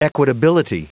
Prononciation du mot : equitability
equitability.wav